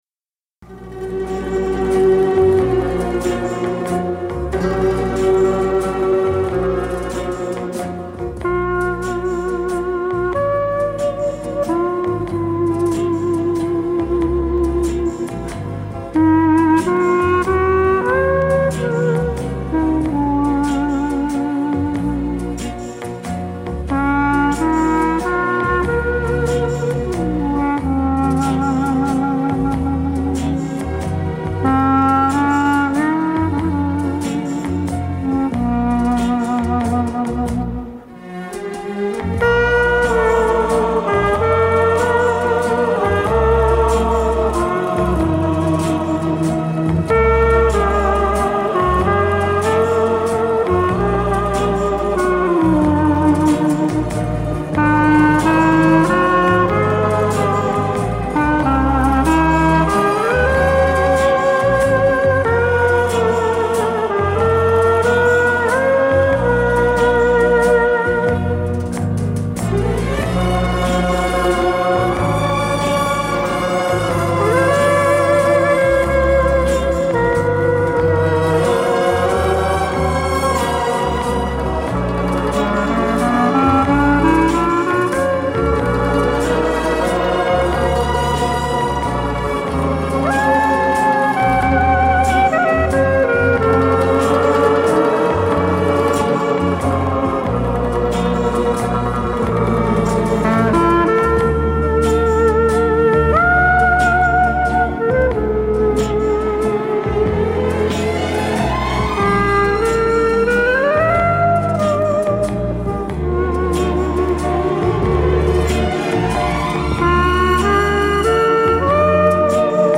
Главное достаточно чисто.